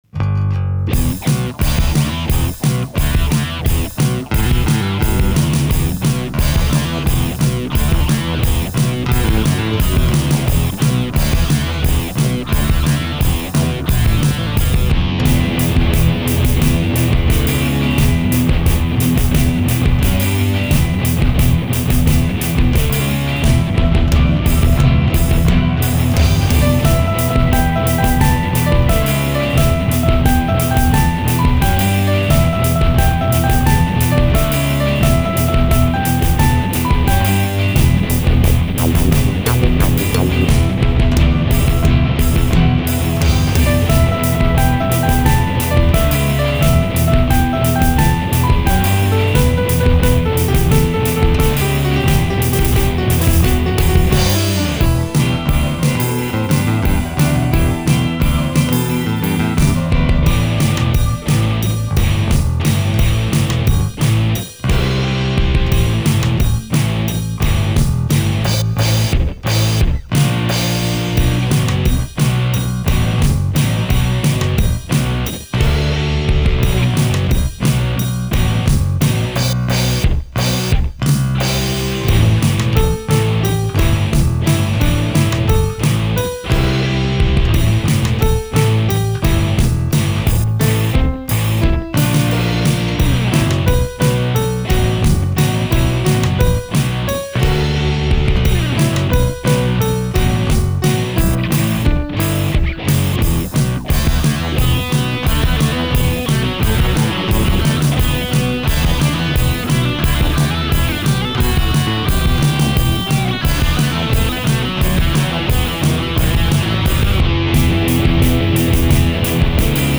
Lead Guitars/
Drums/Percussion